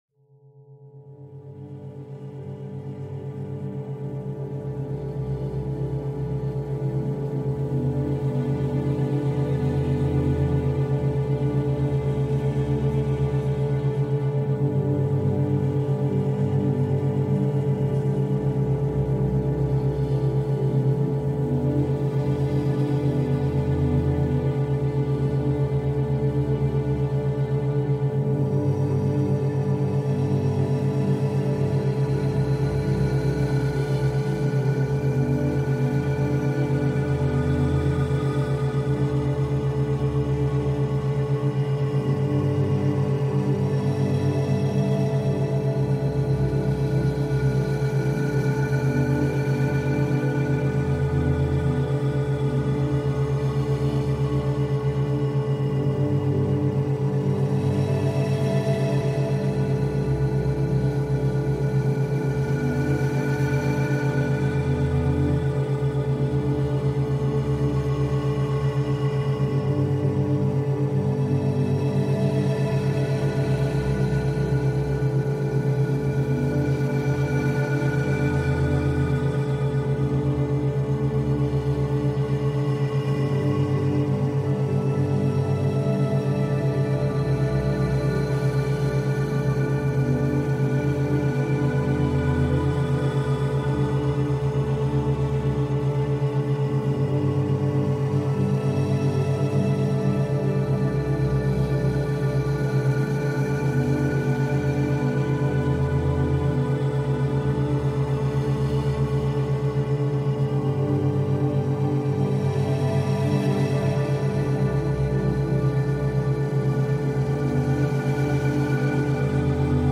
Focus urbain essentiel · méthode de bruit de fond éprouvée pour la réussite académique